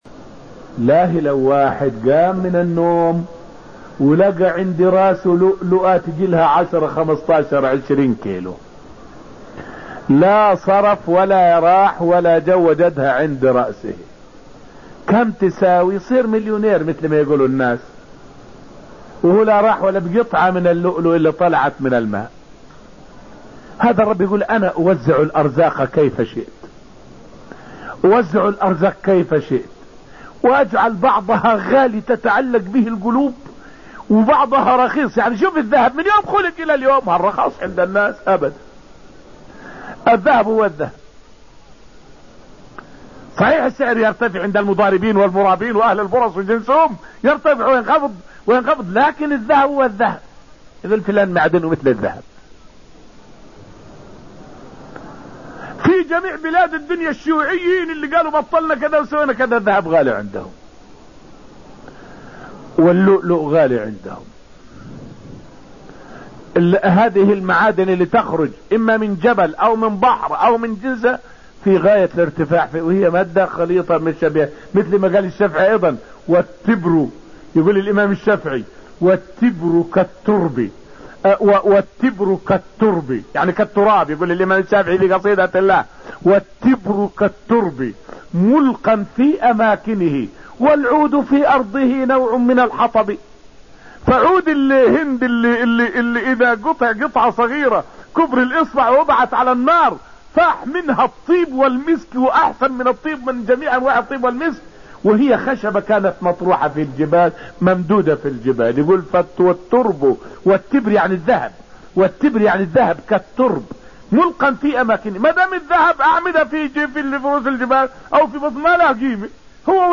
فائدة من الدرس السابع من دروس تفسير سورة الرحمن والتي ألقيت في المسجد النبوي الشريف حول حكمة الله في جعل بعض المعادن ثمينة.